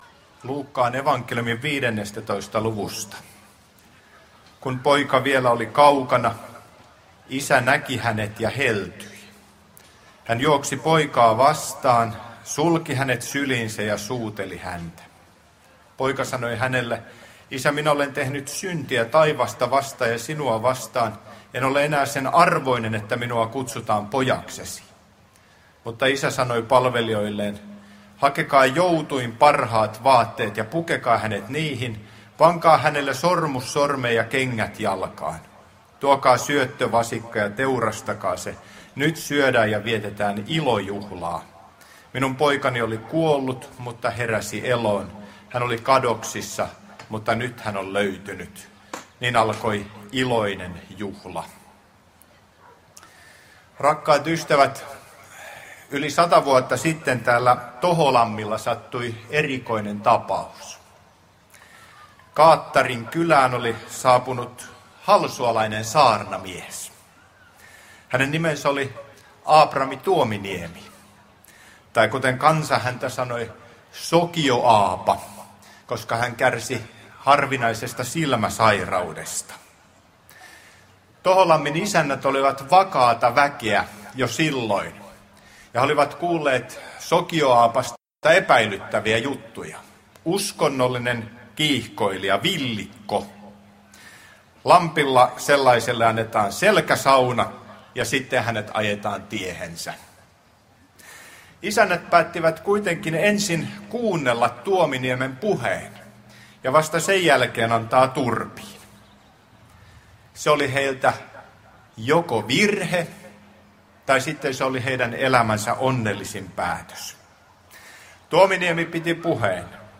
Toholammin evankeliumijuhlassa lauantaina Pohjana Luuk. 15:11–32